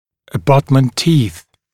[ə’bʌtmənt tiːθ][э’батмэнт ти:с]опорные зубы (при протезировании)